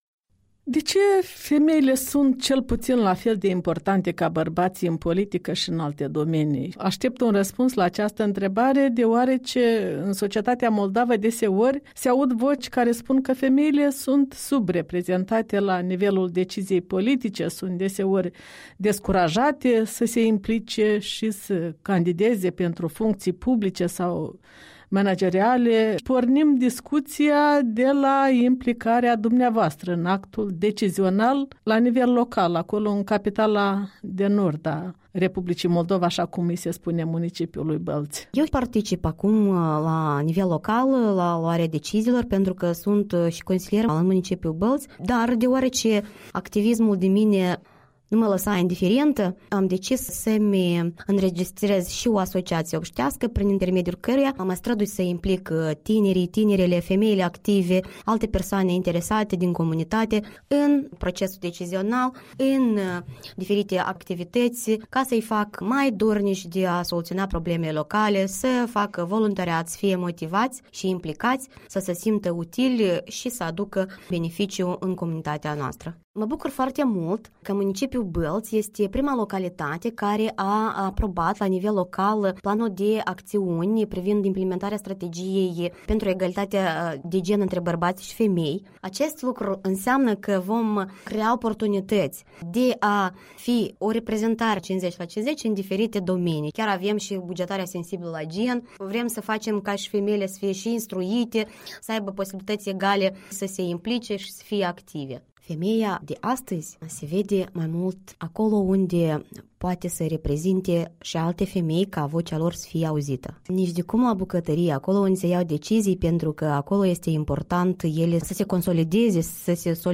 Interviu cu consiliera municipală din Bălți Renata Grădinaru.